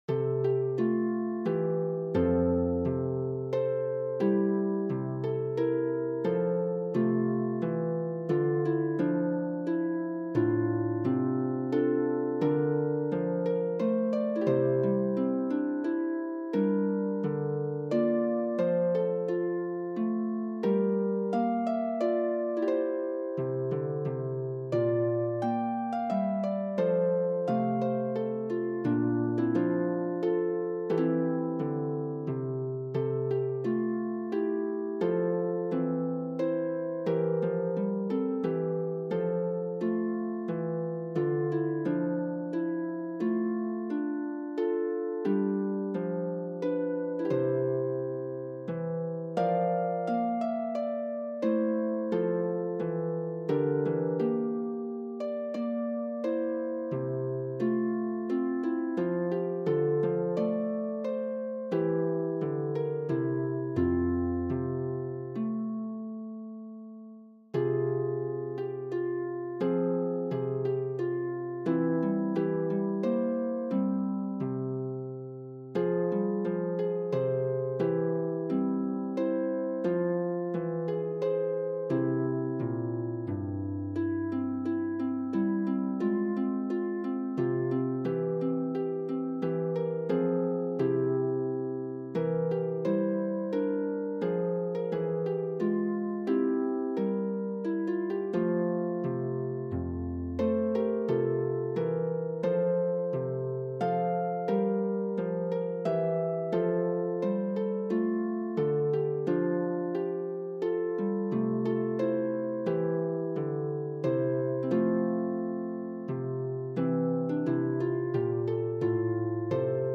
There is a key change requiring the A lever.